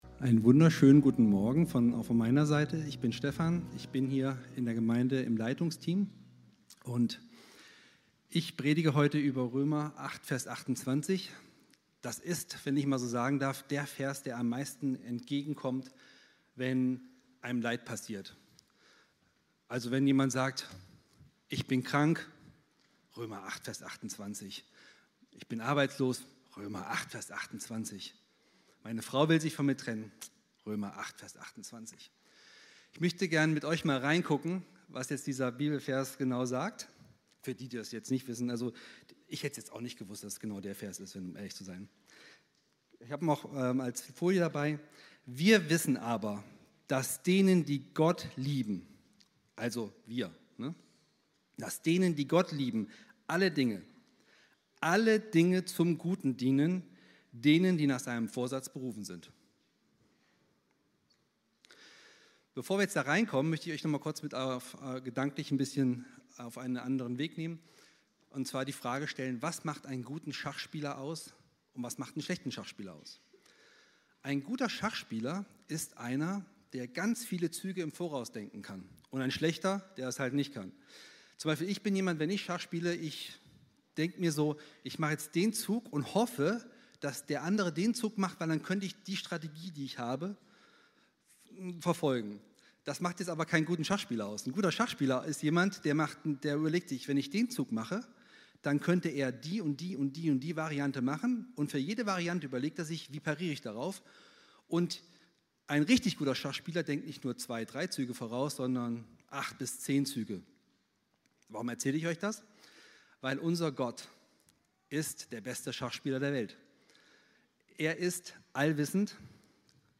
Predigten der Gottesdienste im Jesus Centrum Kassel